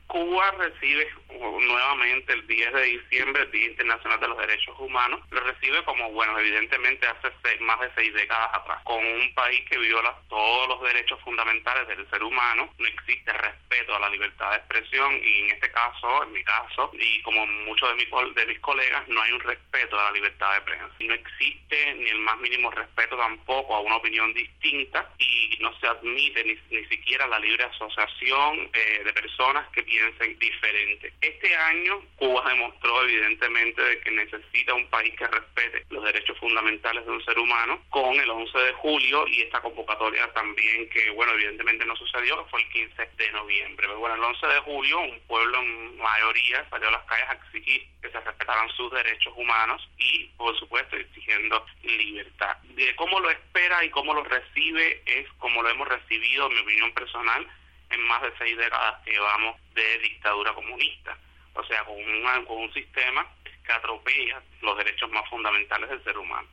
periodista independiente y los Derechos Humanos en Cuba